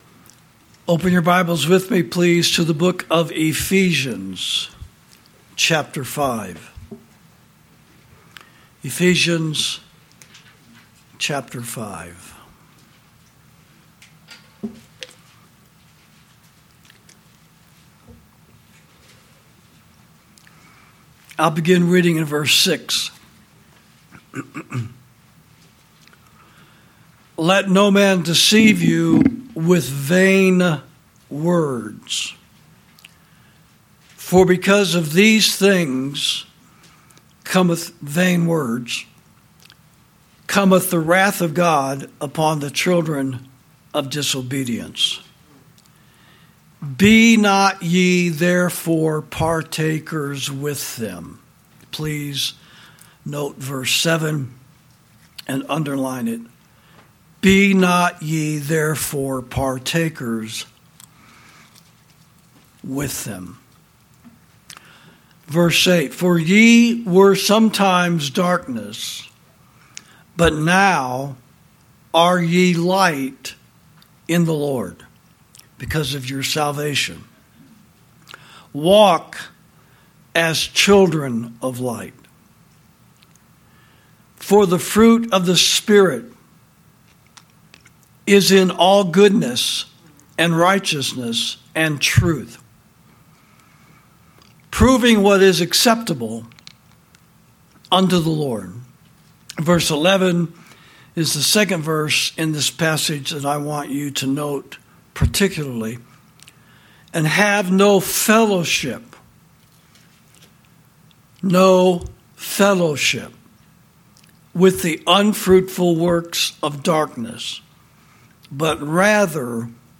Sermons > Evangelicals' Divine Litmus Test